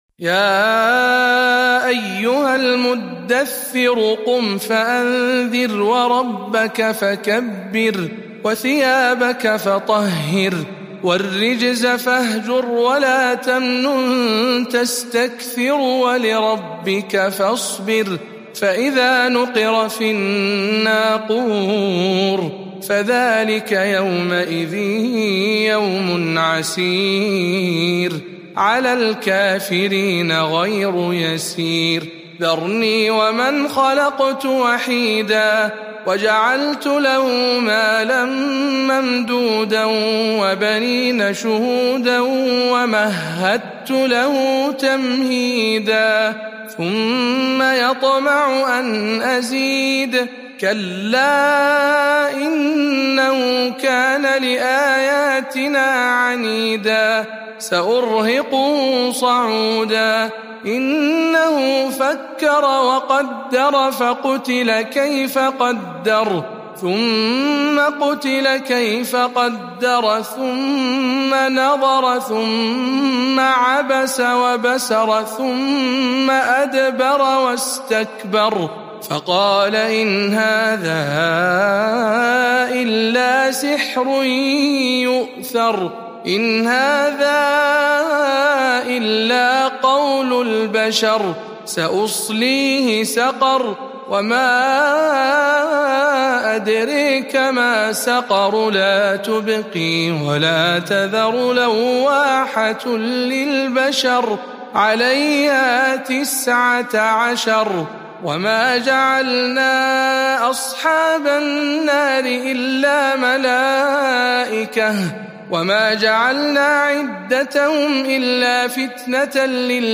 سورة المدثر برواية شعبة عن عاصم